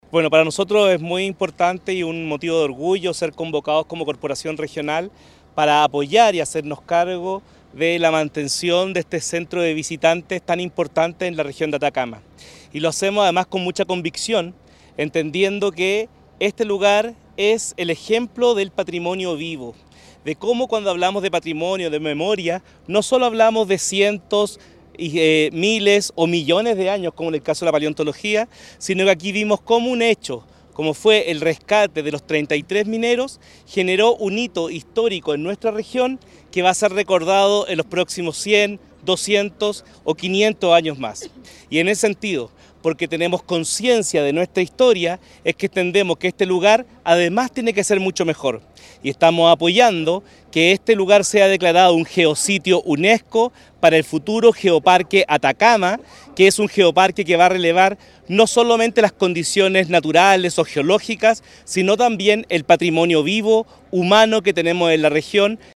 Durante la ceremonia, el Gobernador Regional de Atacama, Miguel Vargas, destacó la importancia de este paso para el desarrollo turístico de la zona: